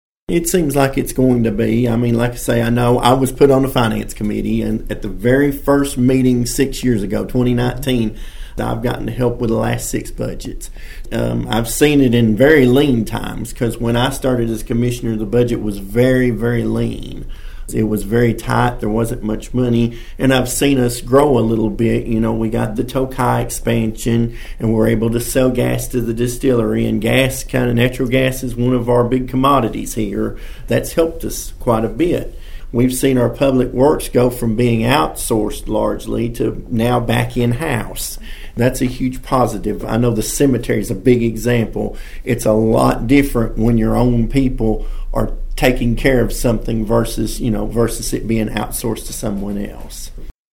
With his experience in city government, Griggs said he foresees a smooth transition.(AUDIO)